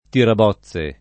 tirabozze [ tirab 0ZZ e ] s. m.; inv.